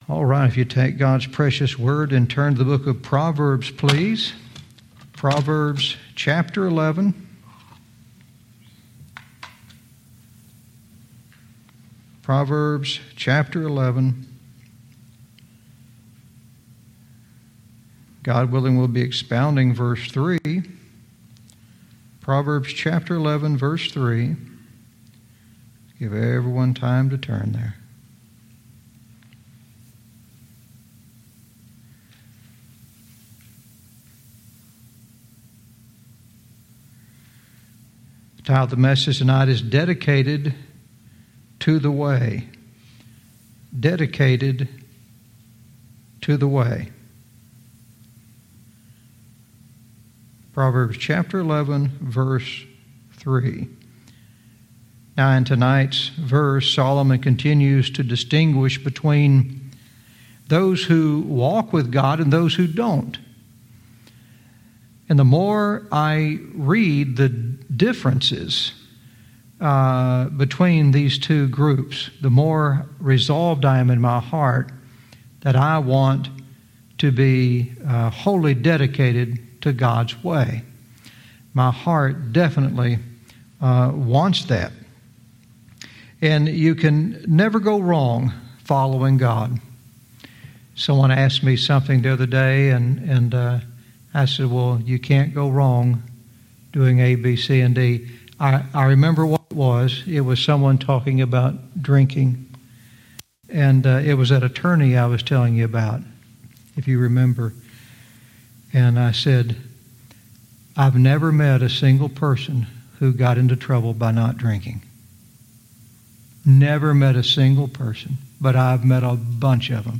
Verse by verse teaching - Proverbs 11:3 "Dedicated to the Way"